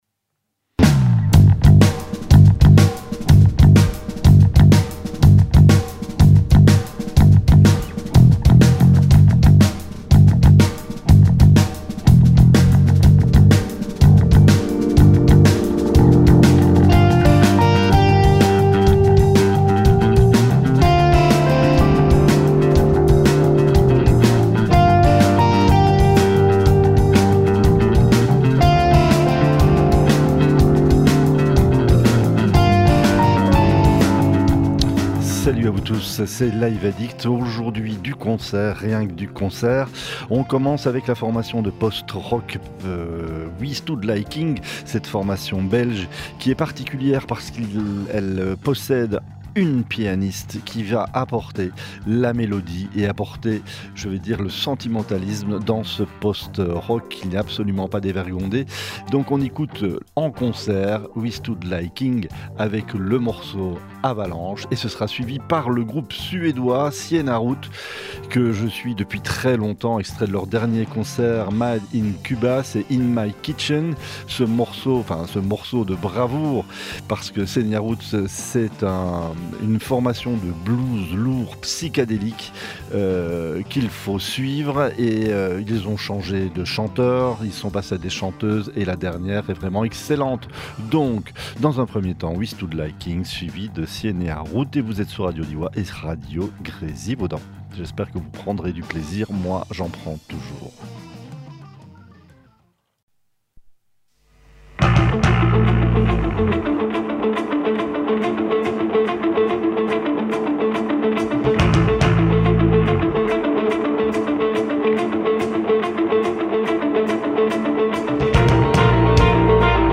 rock , rock progressif